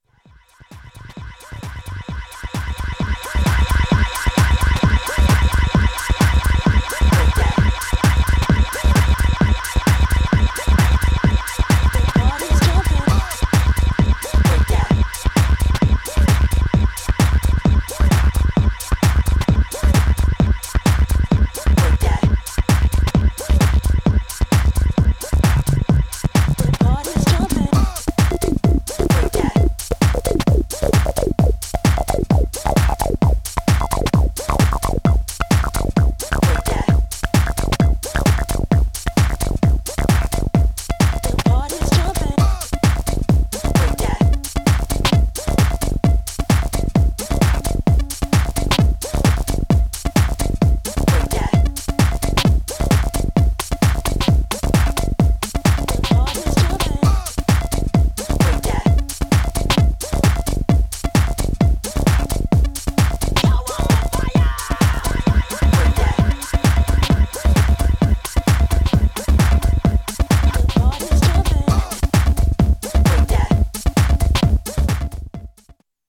Styl: Hip Hop, House